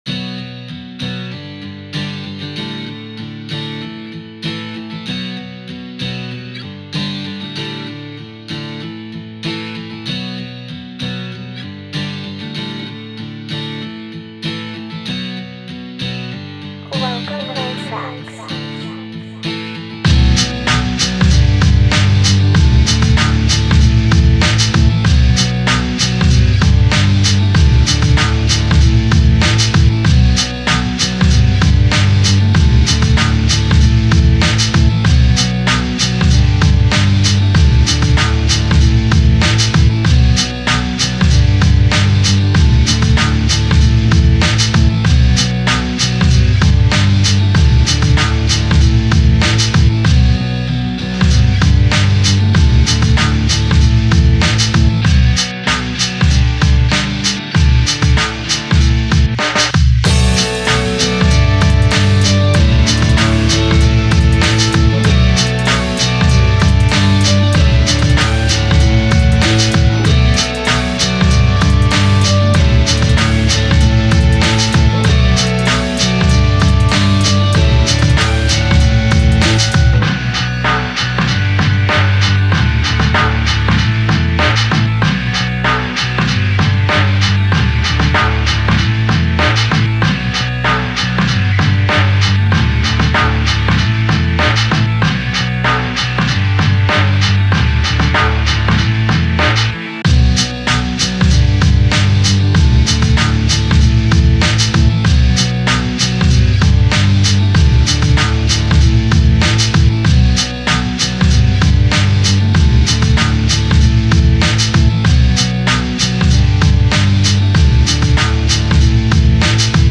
Rock Type Beat HipHop/Rap/Bgm | Rock/Guitar/Alternative